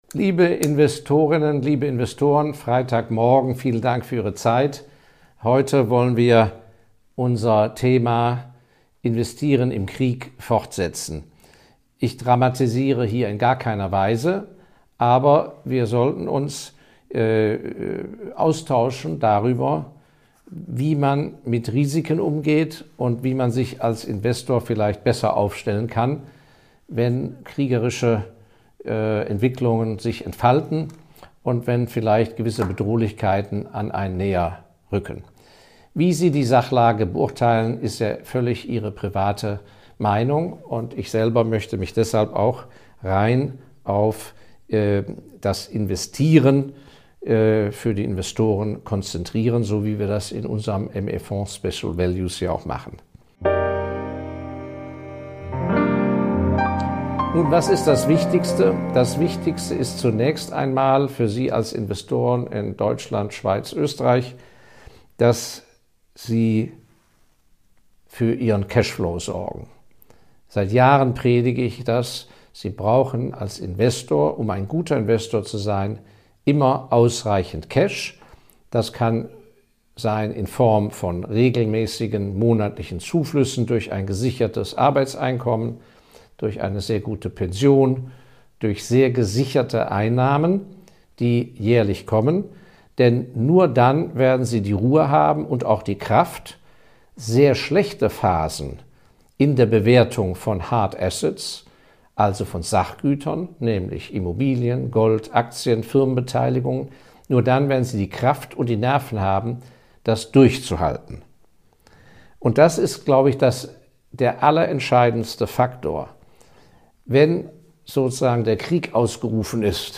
Warum das „Value Investing” wie es die meisten Investoren interpretieren tot ist und vieles mehr, möchte ich in diesem Podcast in Form von spannenden Interviews beantworten...